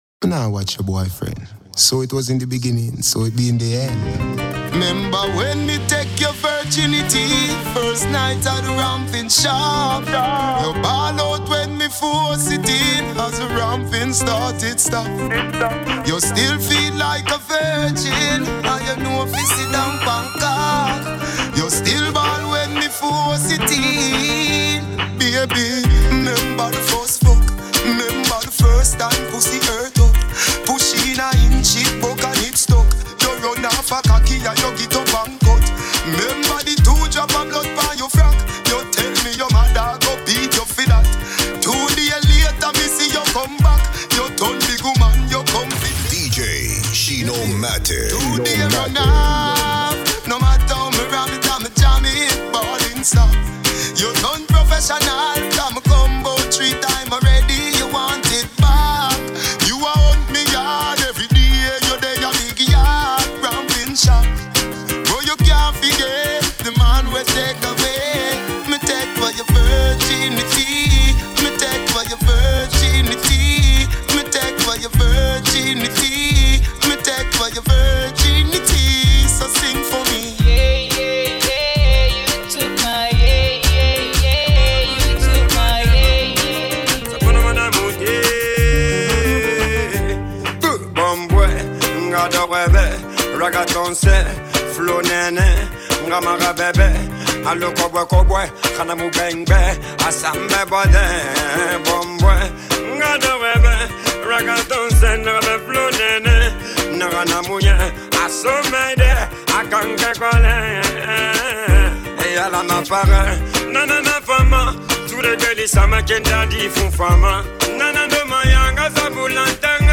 mix exclusivo